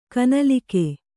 ♪ kanalike